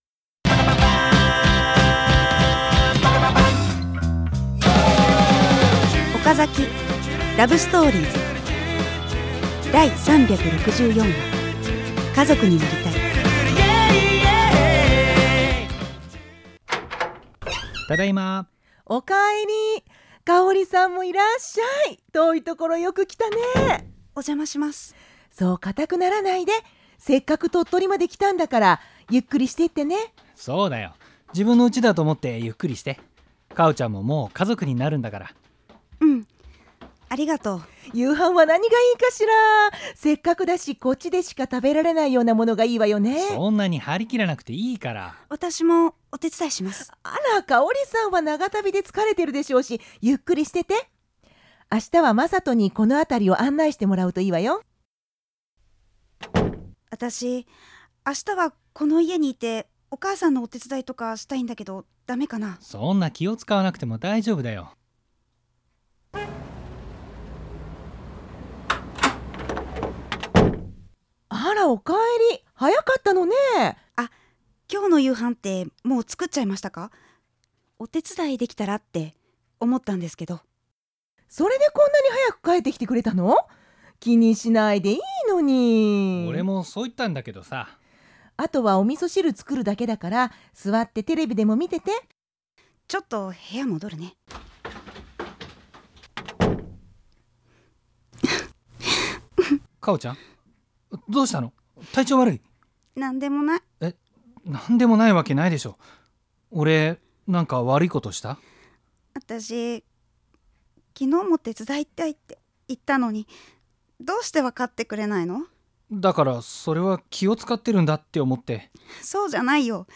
身近でリアルな恋愛をドラマにしてお送りする「OKAZAKI LOVE STORIES」。